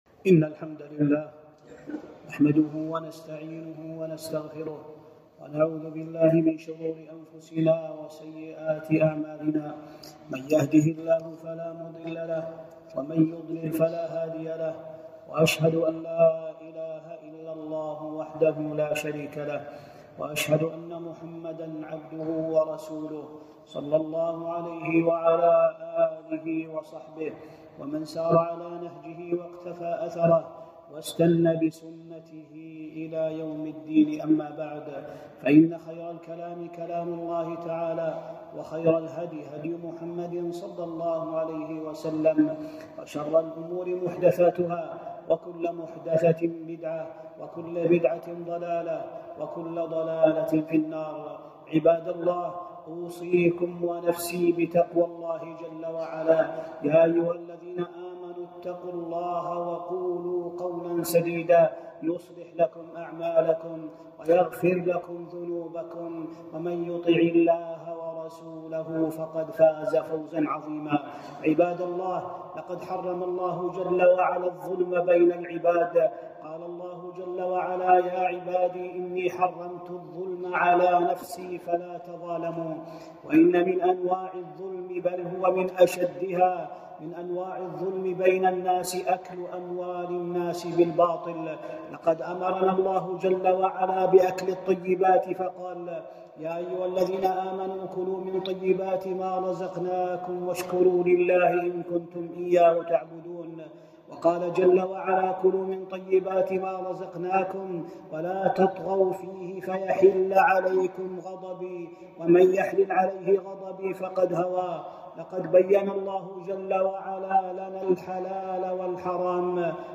خطبة - خطبة حرمة أكل أموال الناس.